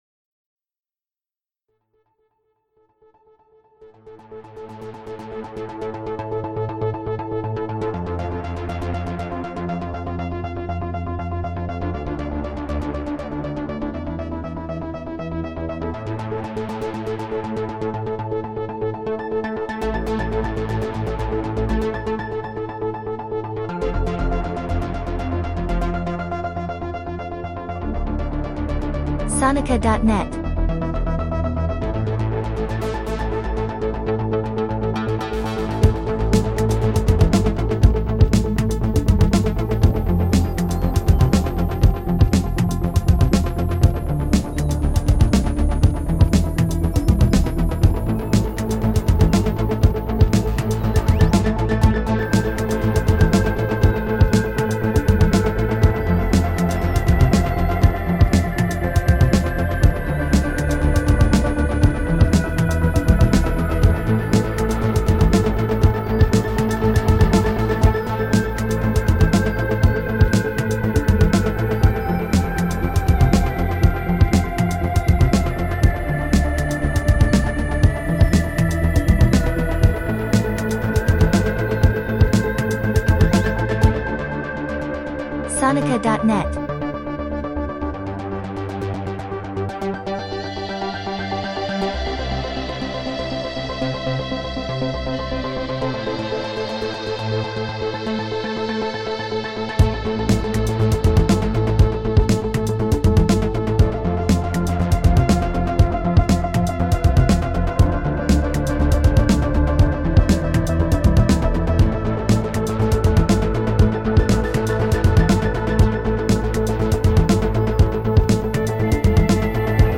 Electronic music project